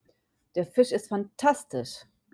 (Der Fisch ist fan-TAS-tisch)